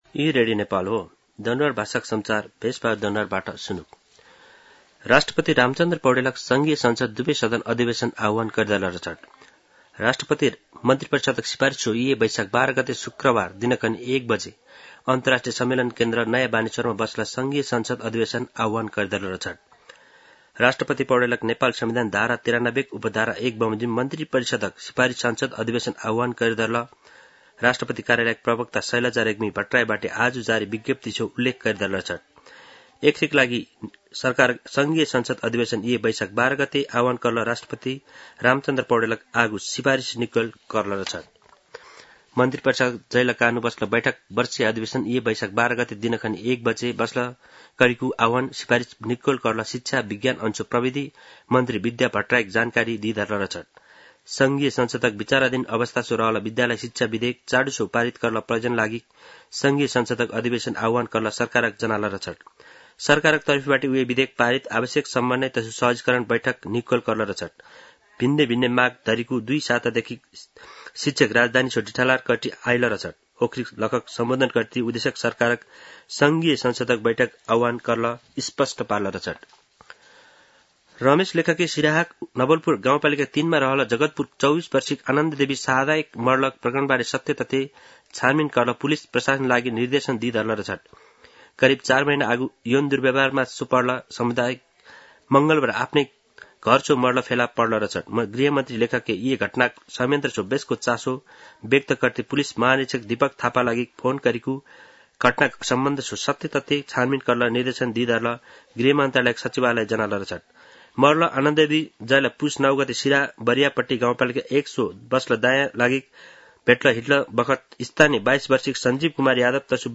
दनुवार भाषामा समाचार : ३ वैशाख , २०८२